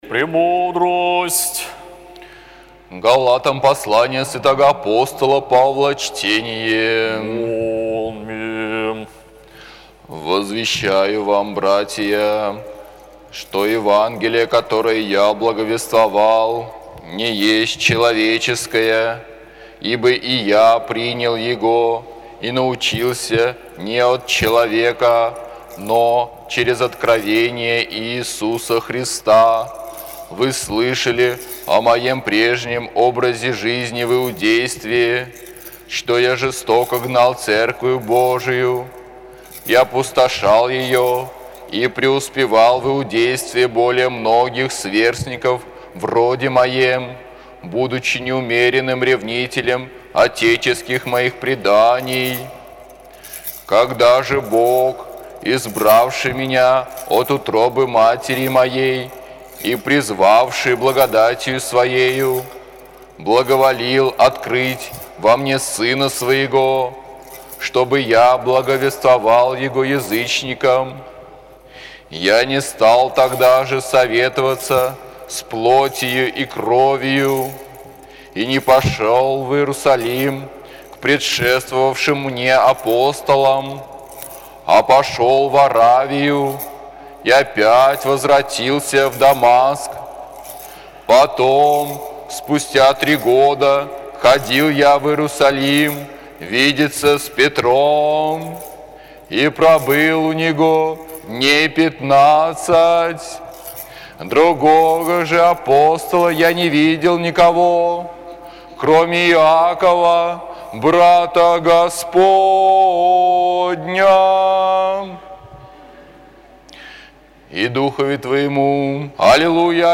АПОСТОЛЬСКОЕ ЧТЕНИЕ НА ЛИТУРГИИ